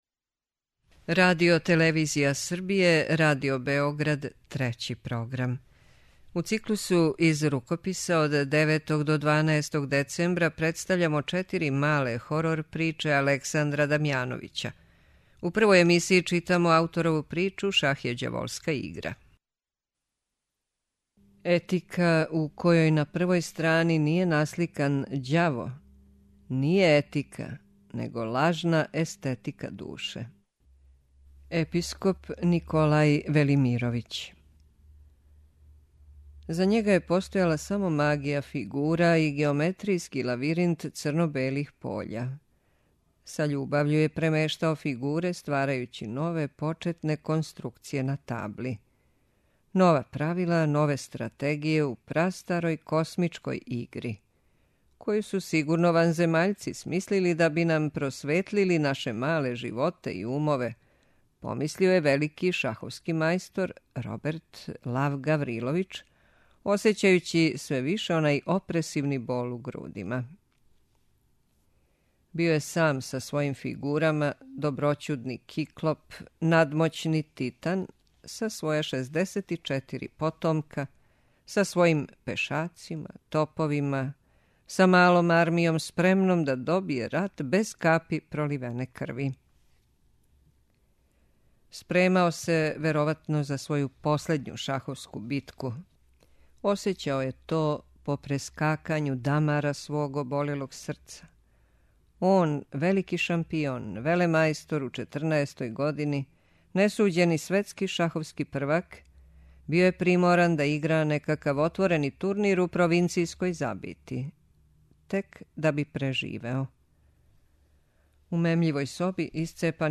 преузми : 42.60 MB Књига за слушање Autor: Трећи програм Циклус „Књига за слушање” на програму је сваког дана, од 23.45 сати.